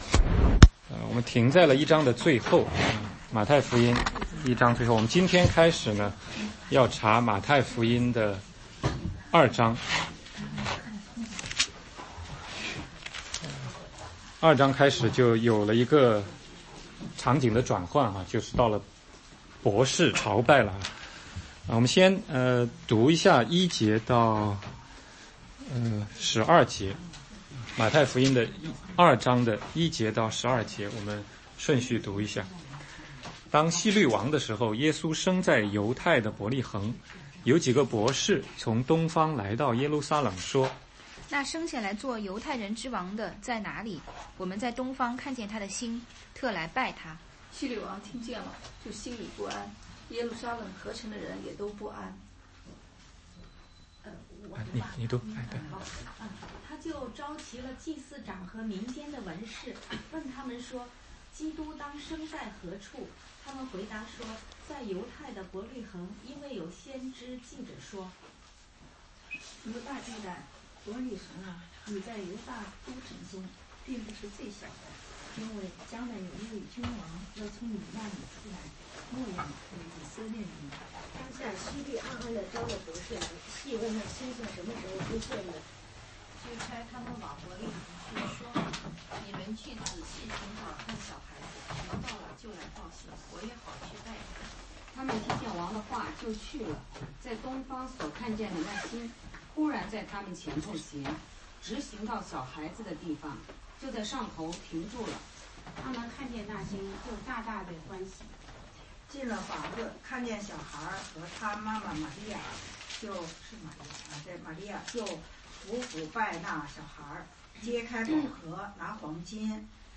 16街讲道录音 - 马太福音2章1-11